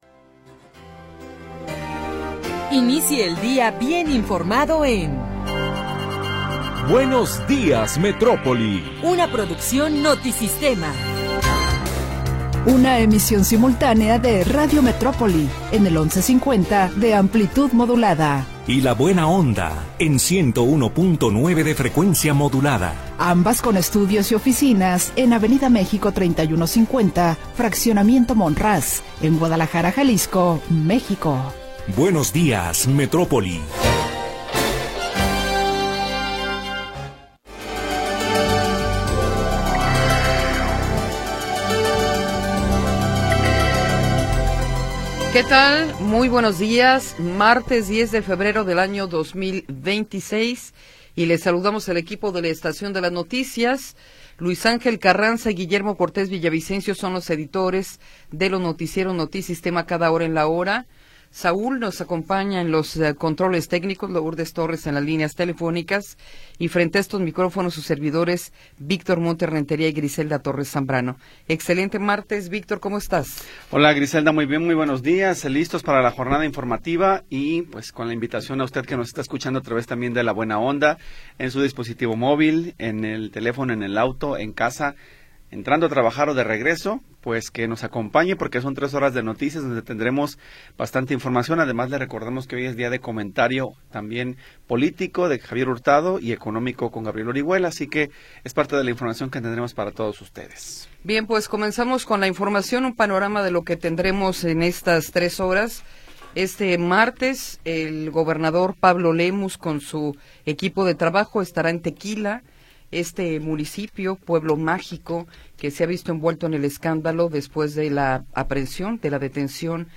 Primera hora del programa transmitido el 10 de Febrero de 2026.